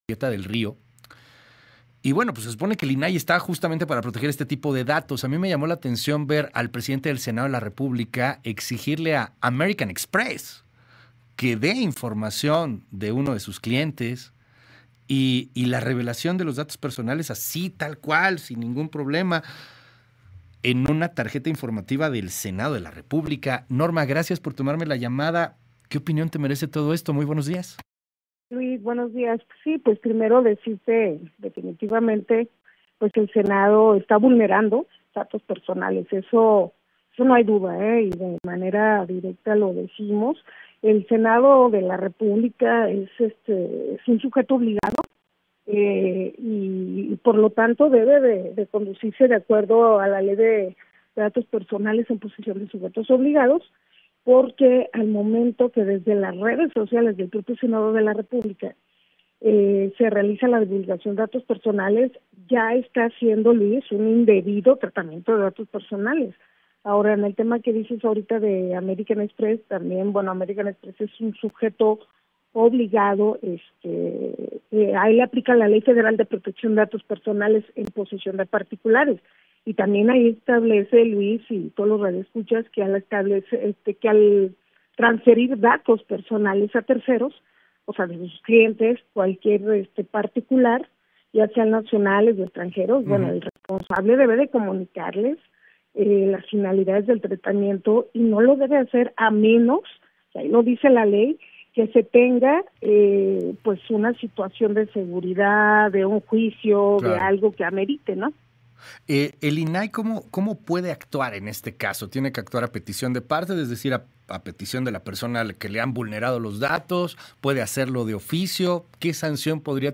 Entrevista con Luis Cárdenas - julietadelrio